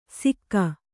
♪ sikka